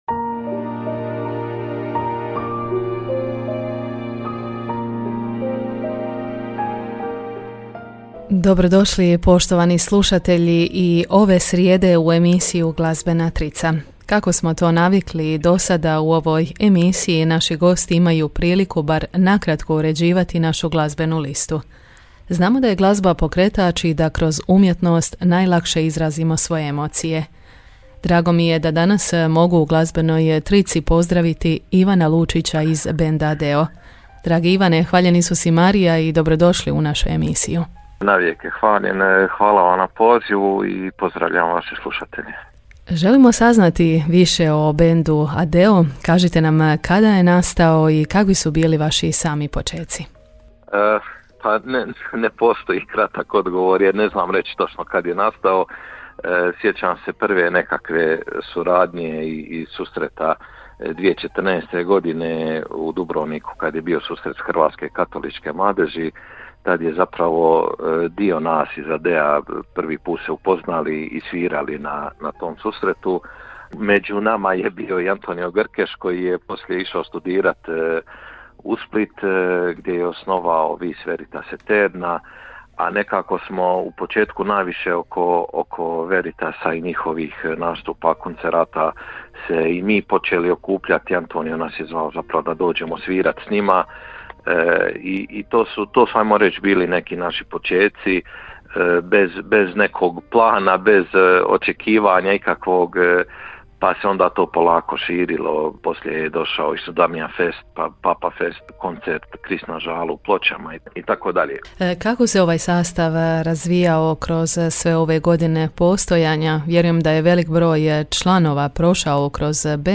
Emisija je ovo u kojoj naši gosti bar na kratko uređuju naš glazbeni program, birajući tri drage skladbe.